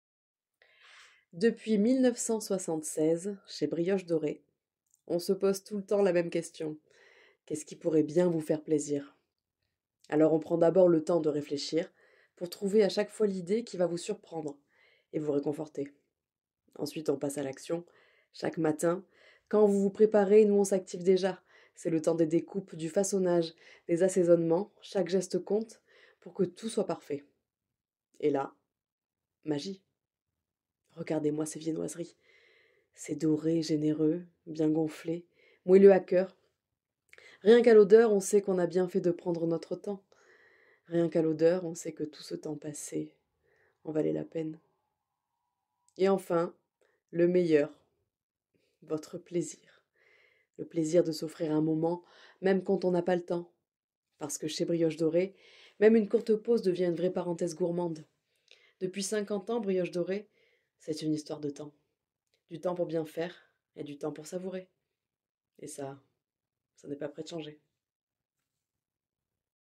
Bandes-son
- Mezzo-soprano